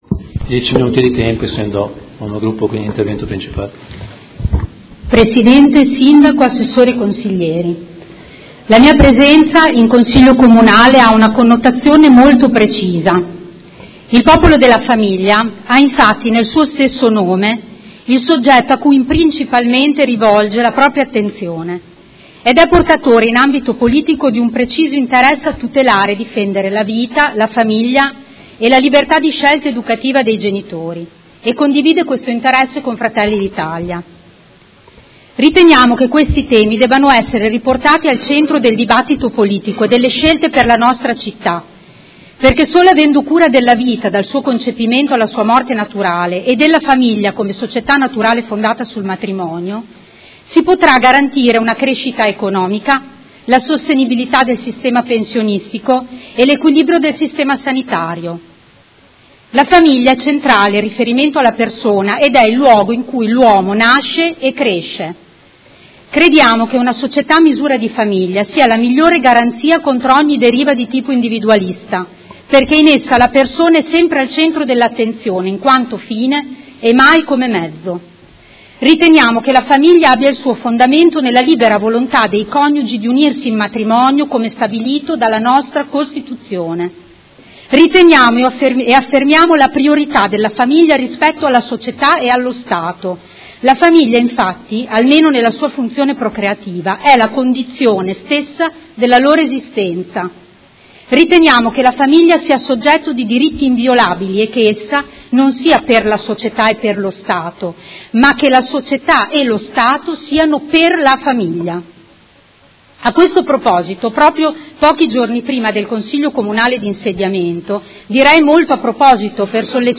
Elisa Rossini — Sito Audio Consiglio Comunale
Dibattito su proposta di deliberazione: Indirizzi Generali di Governo 2019-2024 - Discussione e votazione